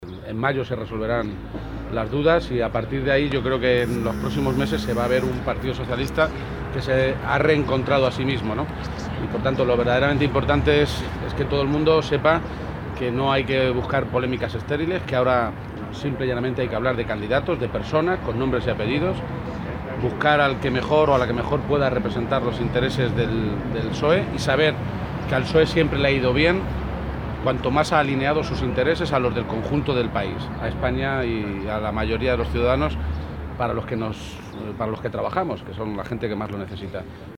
García-Page, que realizó estas declaraciones momentos antes del inicio del Comité Federal que se celebra hoy en Madrid, indicó que lo verdaderamente importante es afrontar el debate de las primarias con altura de miras para hablar de los problemas que tiene nuestro país y como pueden los socialistas contribuir a resolverlos.
Cortes de audio de la rueda de prensa